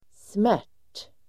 Uttal: [smär_t:]